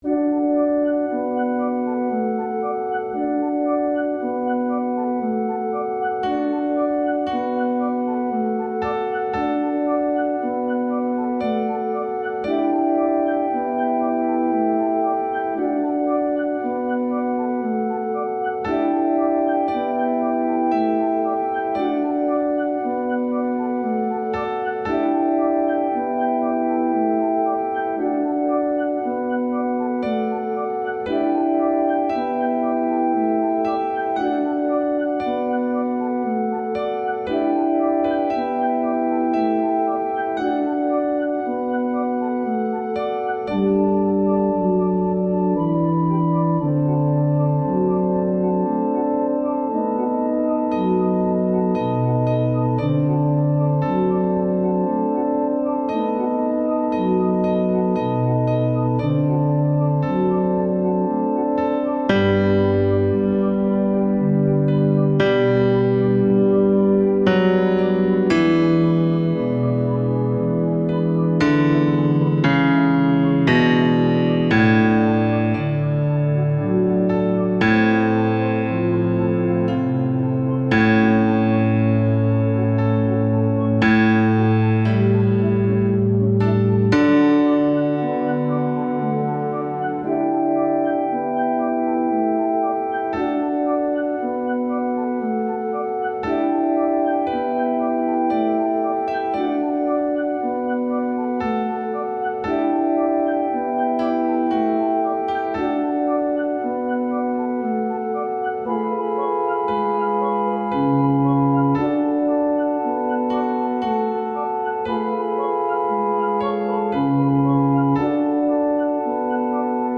Bajo II
Bajo-II-In-Paradisum-MUSICA-Mp3.mp3